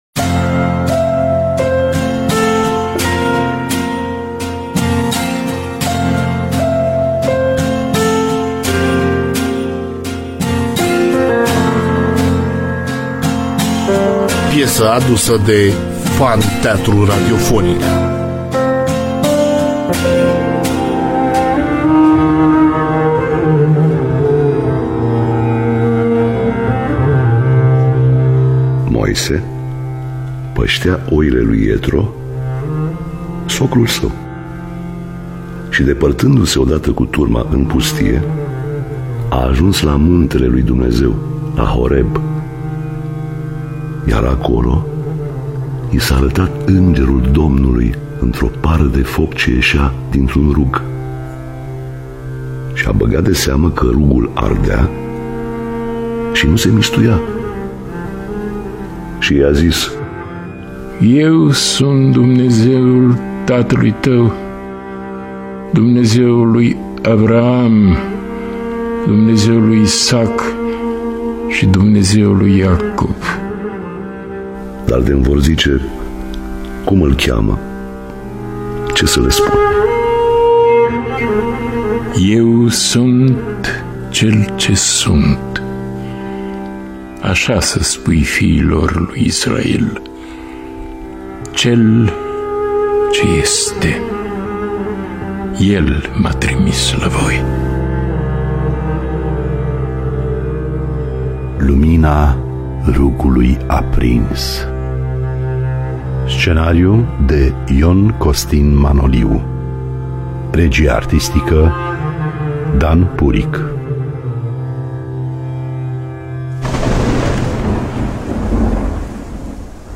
Biografii, Memorii: Lumina Rugului Aprins (2019) – Teatru Radiofonic Online